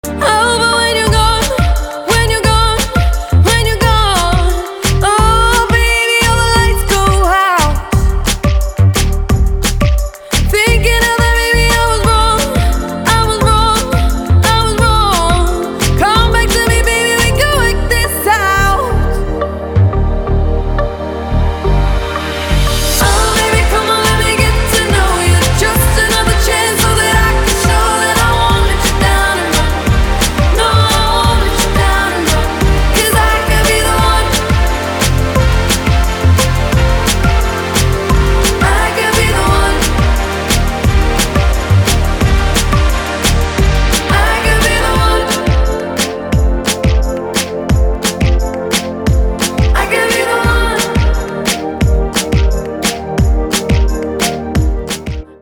• Качество: 320, Stereo
красивые
спокойные
dark pop